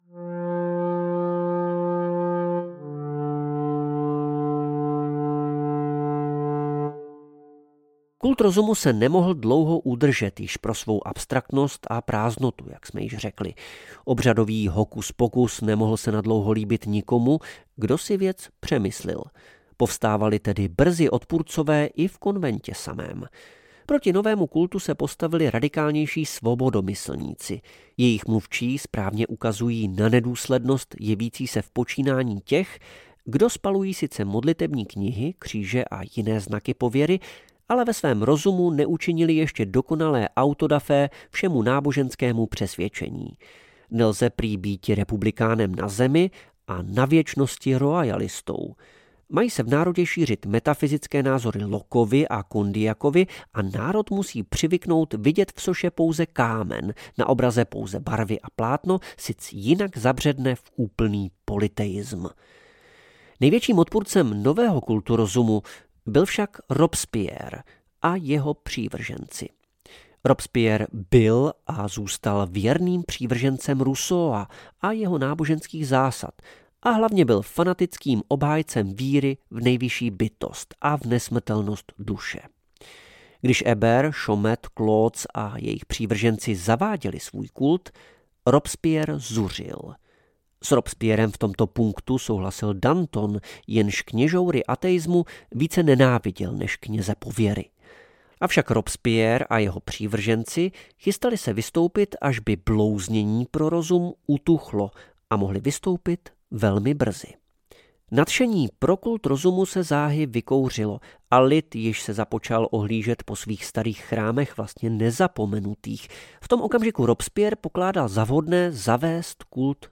Kult rozumu a Nejvyšší bytosti audiokniha
Ukázka z knihy